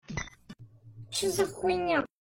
medved zheltyi cho za Meme Sound Effect